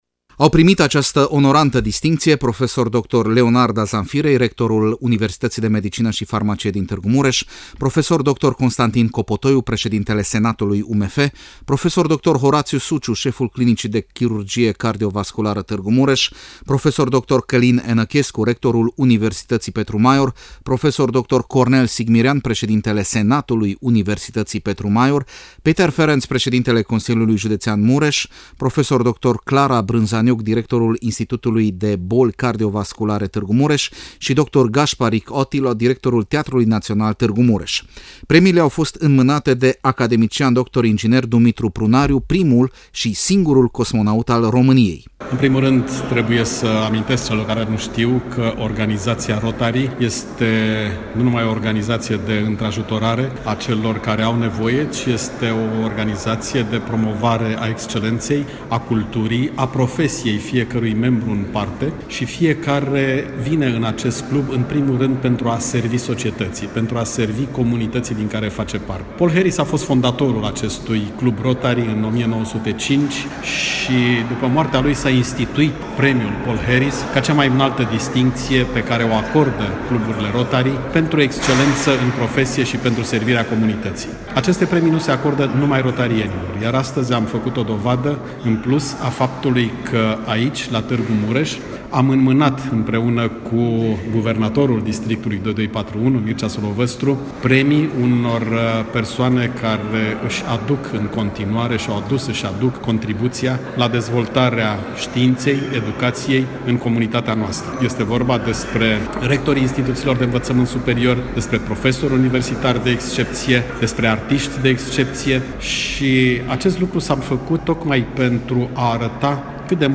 Ceremonia a avut loc joi, 22 iunie, la Sala de Oglinzi a Palatului Culturii din Tîrgu Mureș.